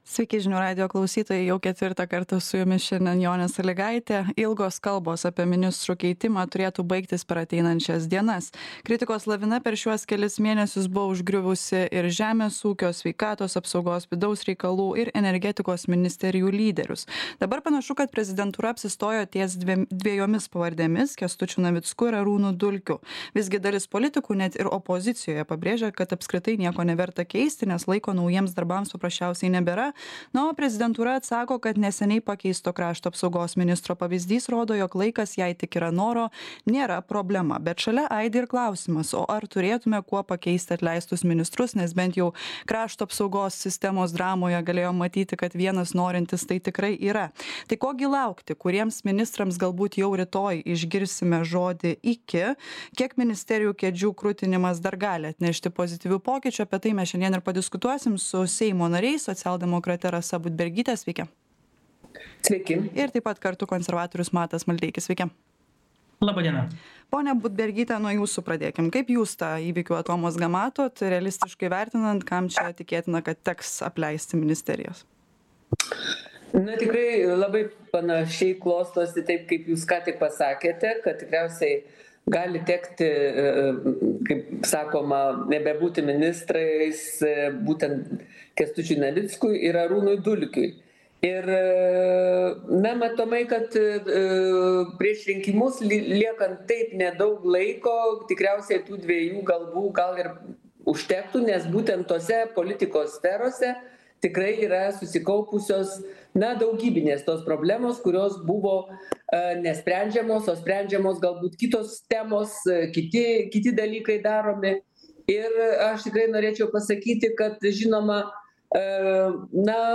Diskutuojame su socialdemokrate Rasa Budbergyte ir konservatoriumi Matu Maldeikiu.
Aktualusis interviu Žiūrėkite Atsisiųsti Atsisiųsti Kuriems ministrams tarsime